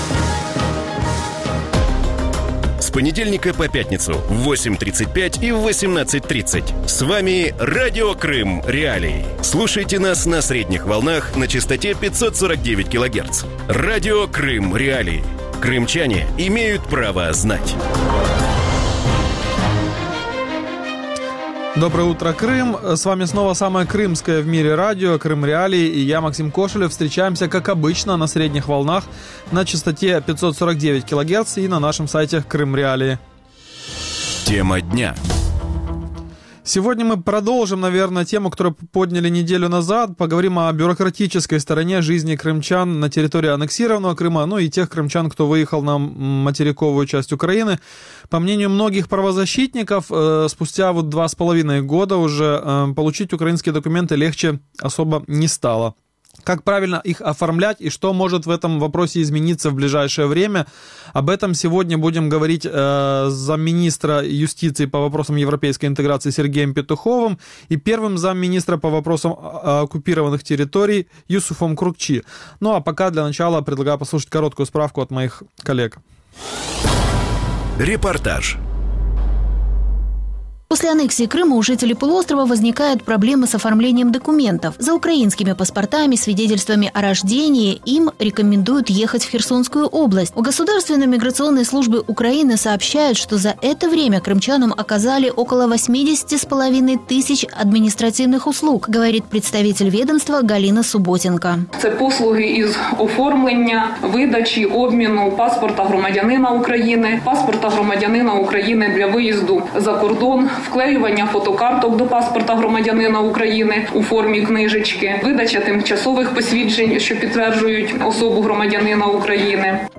Видача свідоцтв про смерть чи народження українського зразка на підставі документів, отриманих на території анексованого півострова, – неприйнятна. Таку думку в ранковому ефірі Радіо Крим.Реалії висловив заступник міністра юстиції з питань європейської інтеграції Сергій Пєтухов.